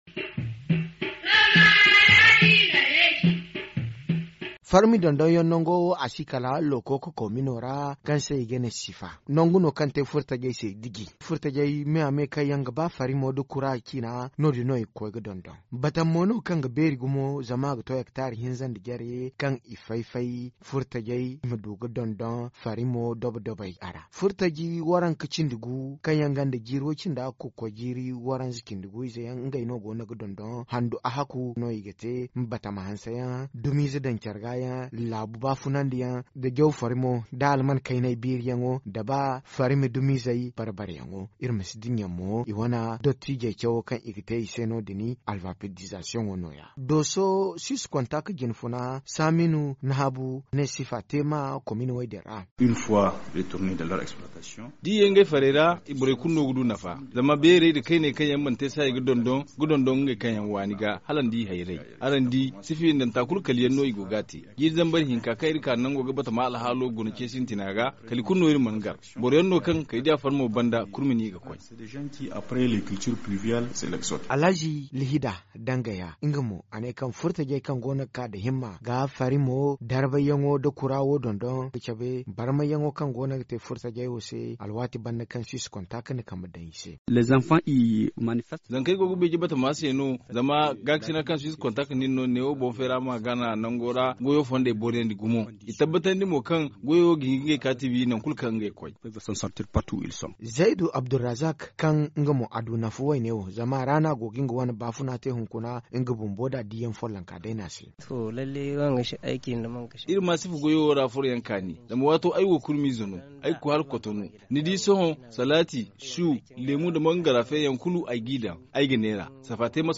Voici le reportage
réalisé à Lokoko